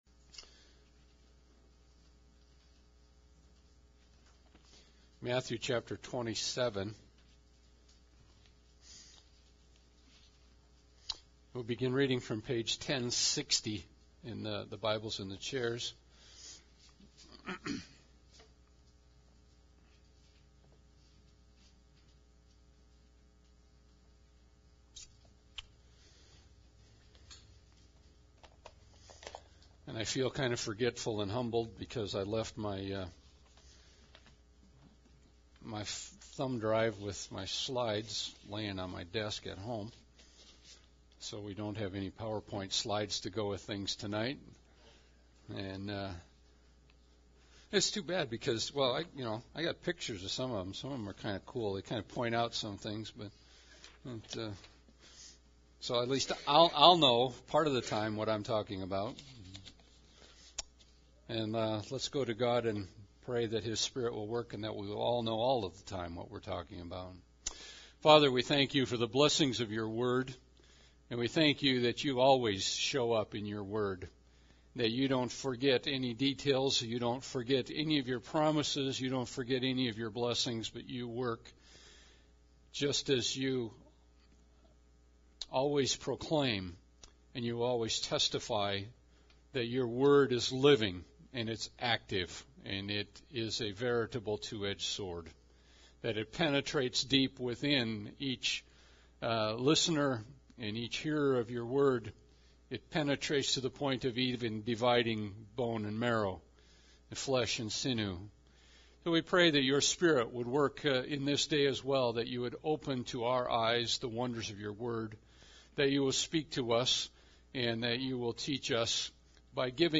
The Irony Of Mis-Perception – Good Friday Service